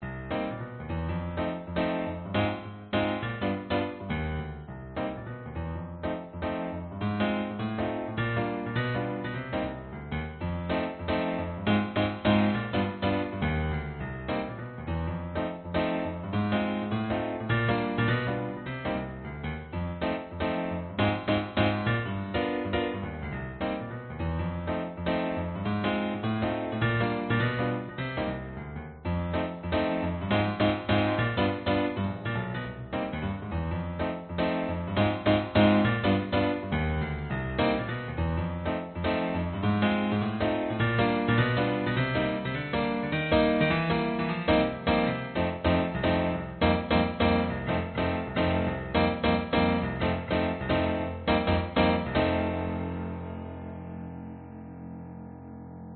描述：C小调快节奏的摇摆爵士钢琴。
Tag: 爵士乐 midi 钢琴 摇摆乐